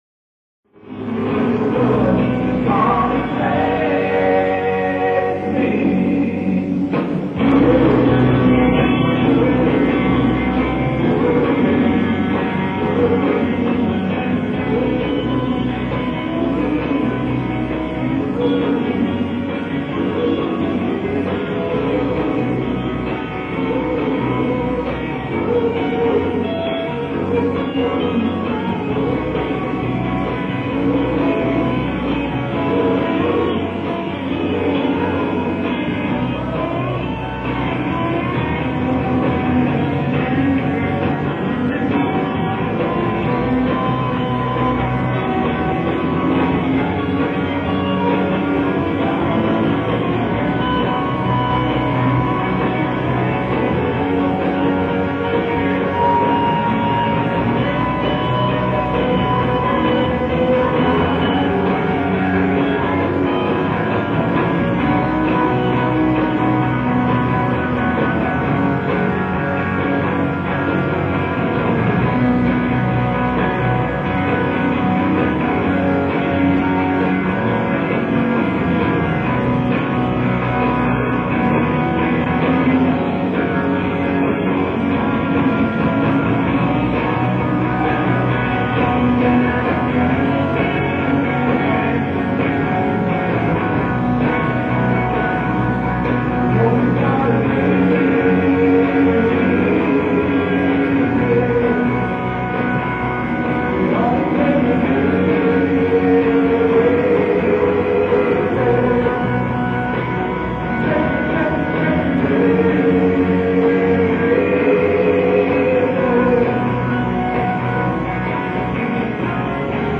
Listen to an audience recording of their set below.